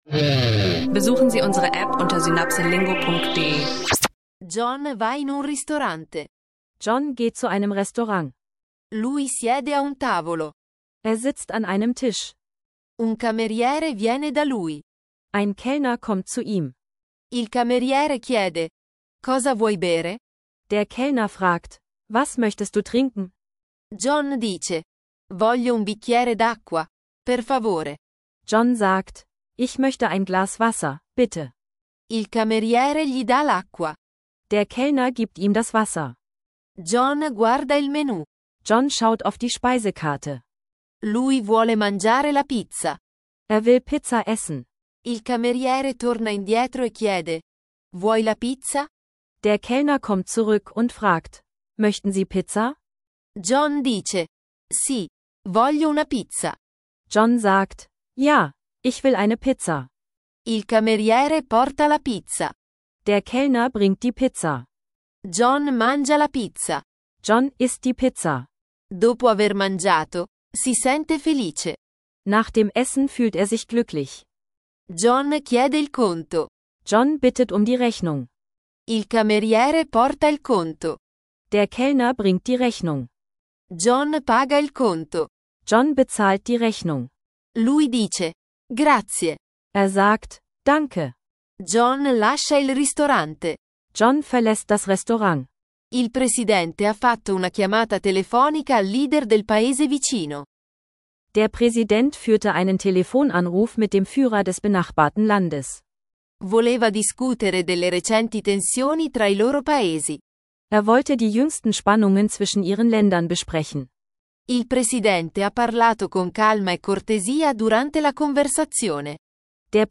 Lerne Italienisch mit Alltagssituationen: Bestellen im Restaurant und diplomatische Gespräche. Dieser Podcast verbindet einfache Dialoge mit praxisnahen Vokabeln und Phrasen rund um Essen bestellen und Friedensgespräche – ideal für Italienisch lernen online und den Alltag.